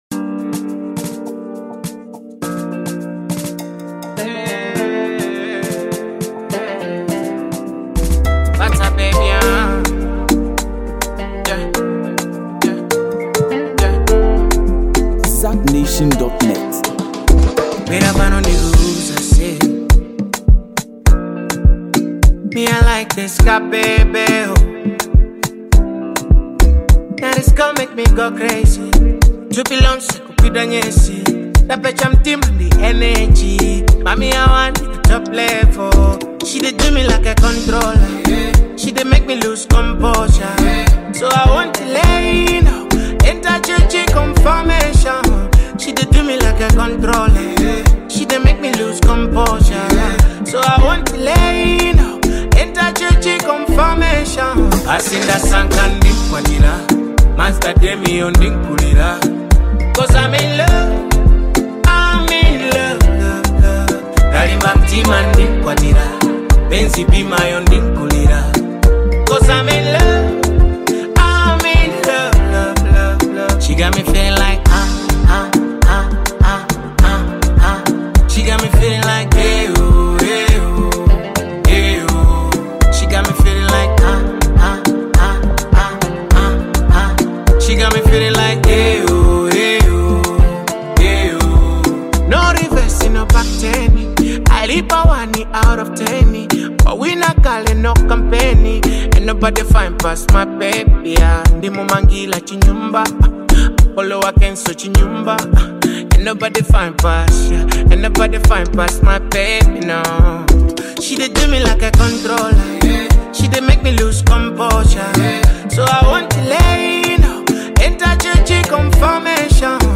This audio is a cool tempo tune for your listening pleasure.